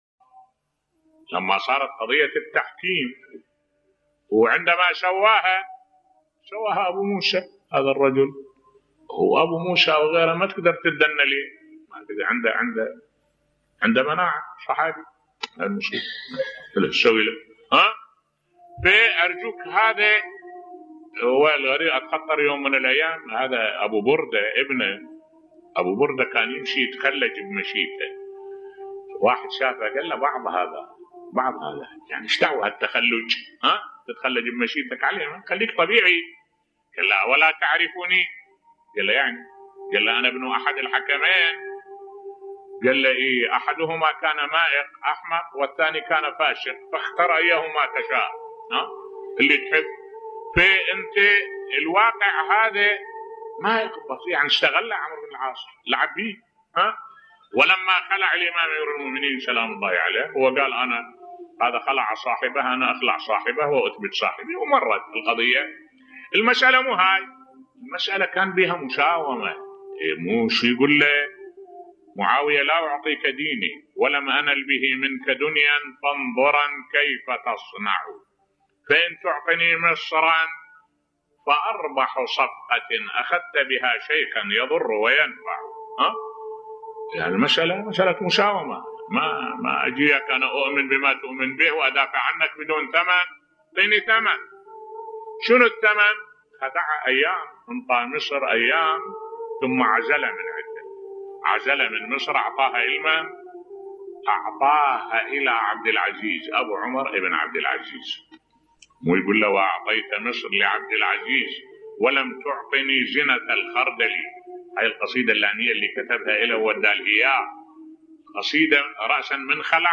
ملف صوتی غدر معاوية و عمر بن العاص بصوت الشيخ الدكتور أحمد الوائلي